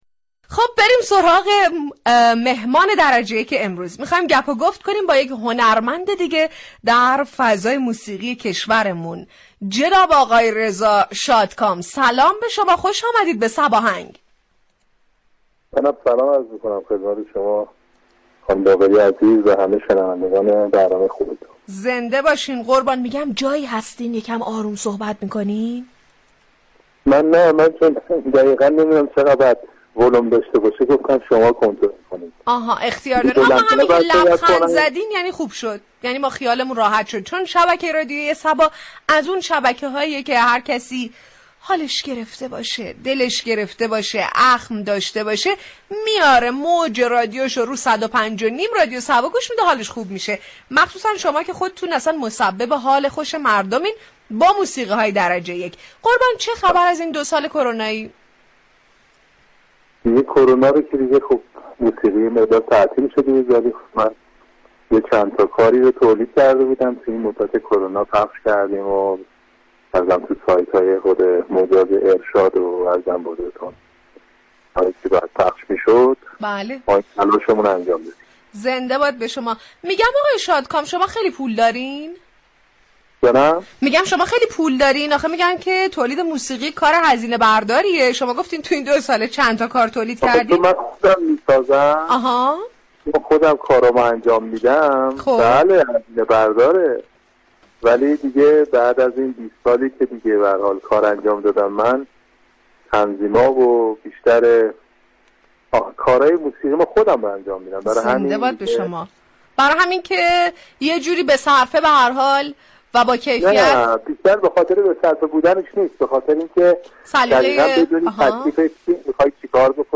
رادیو صبا در برنامه صباهنگ میزبان رضا شادكام خواننده خوب كشورمان شد.